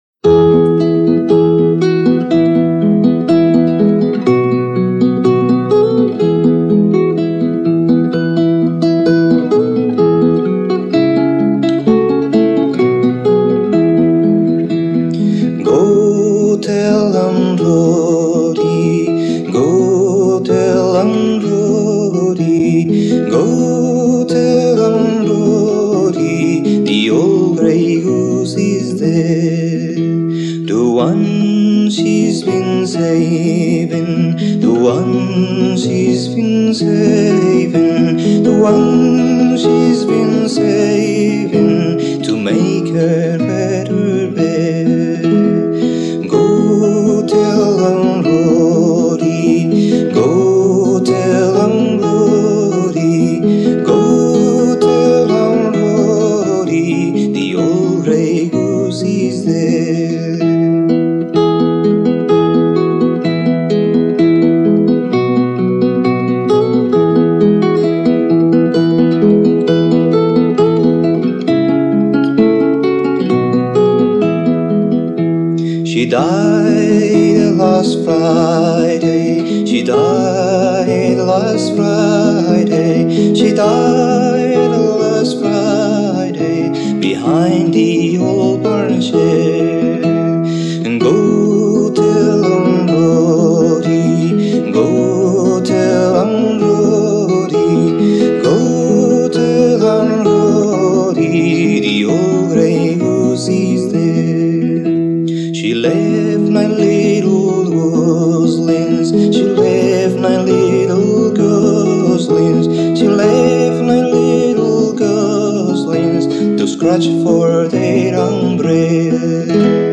Guitarra y voz